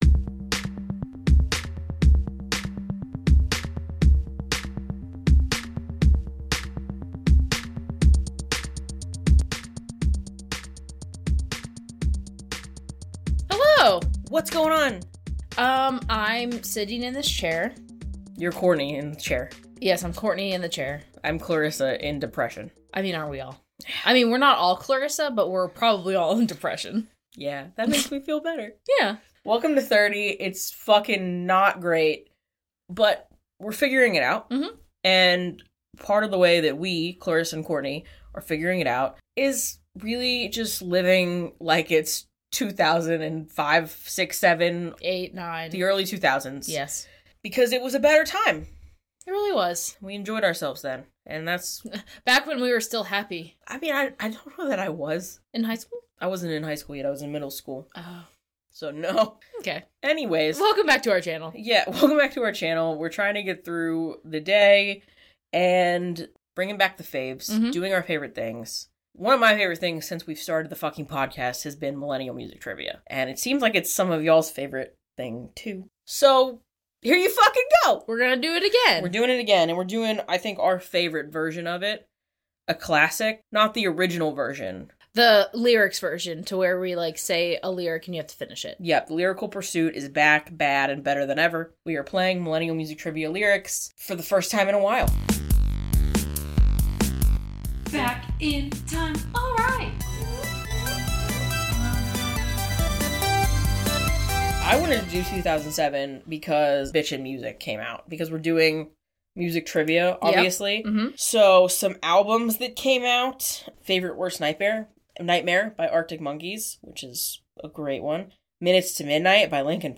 No ownership, monetary compensation, or other benefit is being gained through the mention of these songs, and the brief playing of edited clips is done for the purposes of commentary, education, and review, in line with Fair Use.